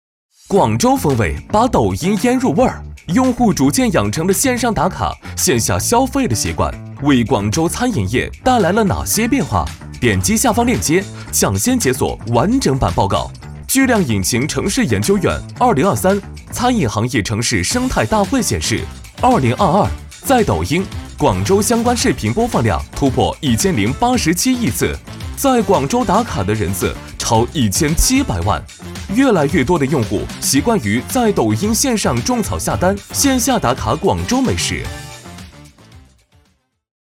男81号